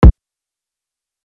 I'M DONE KICK.wav